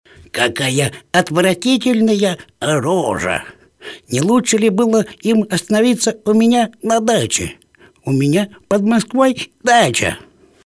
пародия на голос Эраста Гарина, персонажа ф-ма, “Джентльмены удачи”
Категория: мужской | средний 30-60
Характеристика: Пародист